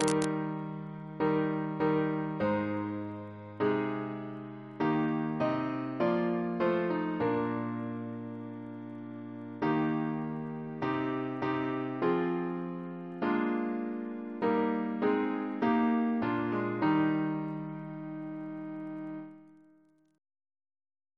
Double chant in E♭ Composer: Samuel Wesley (1766-1837) Reference psalters: ACB: 254; CWP: 25; OCB: 148; PP/SNCB: 171; RSCM: 156